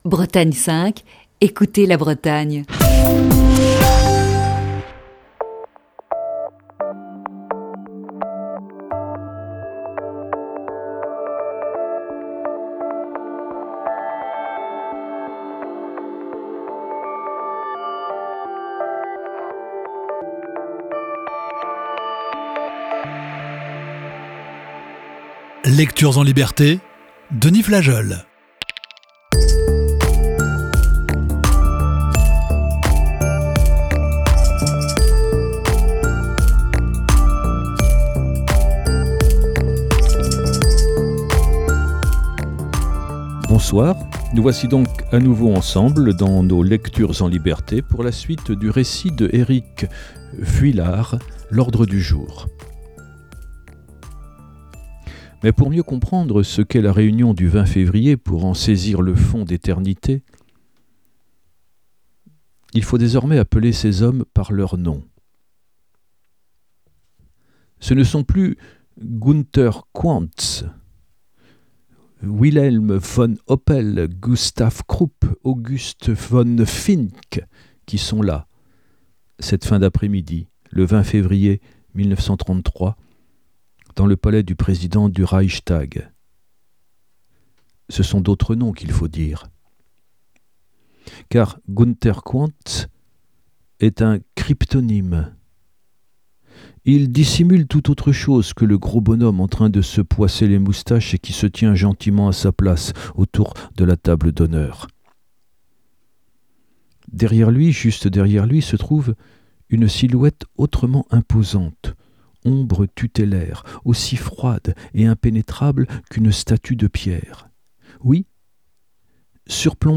Ce soir, il nous propose la deuxième partie de ce récit.